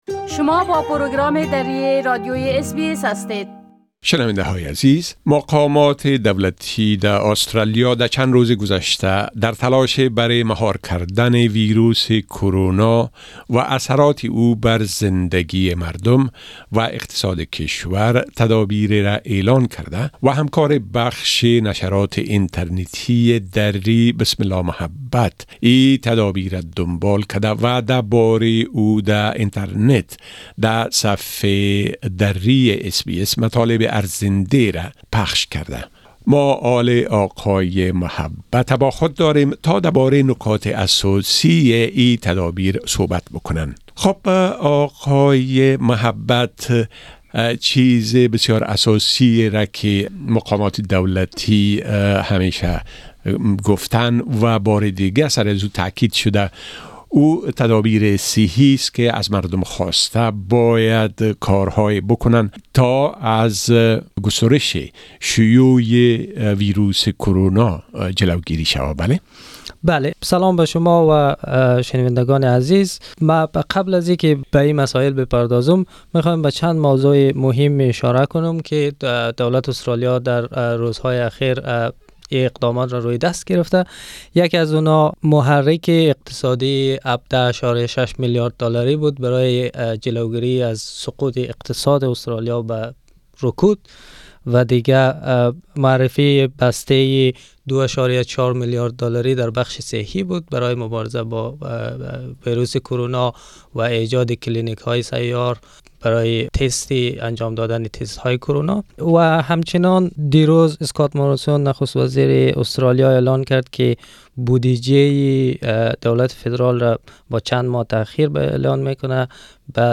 در پی افزایش سریع مبتلایان ویروس کرونا در آسترالیا، مقامات در هفته‌های اخیر اقدامات بی‌سابقه‌ای را برای مهار شیوع این ویروس و همچنان مبارزه با اثرات اقتصادی آن روی دست گرفتند. در این گفت‌وگو این اقدامات را مرور کرده‌ایم.